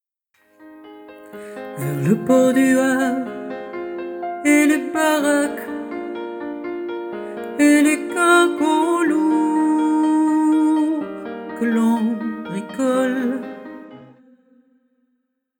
CHANTEUSE JAZZ-POP-RETRO, ANIME VOS EVENEMENTS DANS L'ORNE, LA NORMANDIE ET LES PAYS-DE-LA-LOIRE
Chanteuse de Jazz, Pop et Rétro dans l'Orne, les régions Normandie et Pays de la Loire. événements SEMINAIRES MARIAGES COCKTAILS ANIMATION